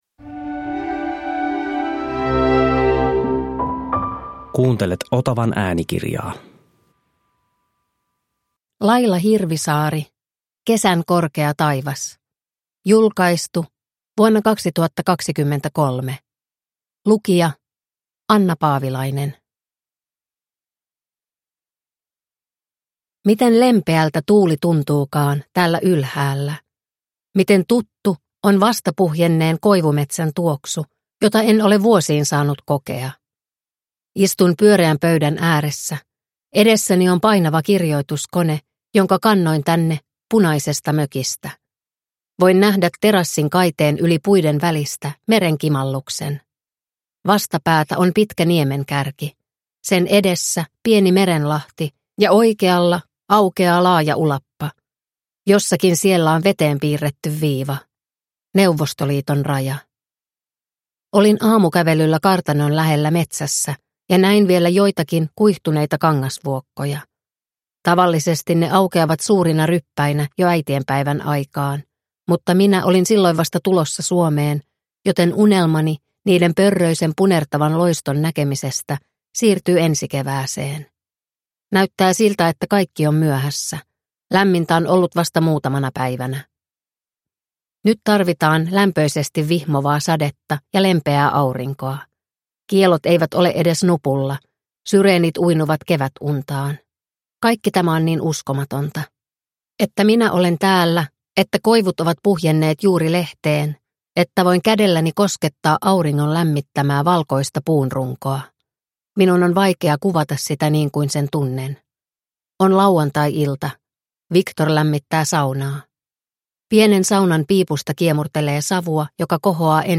Kesän korkea taivas (ljudbok) av Laila Hirvisaari